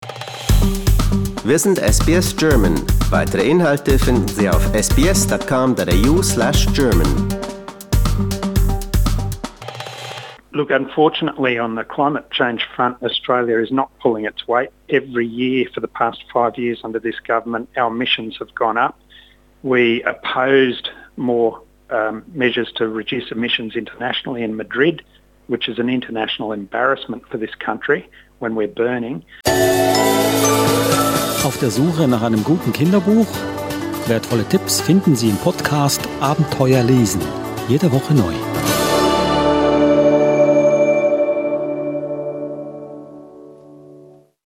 Former Fire and Rescue New South Wales commissioner Greg Mullins, speaking after the UN climate summit in Madrid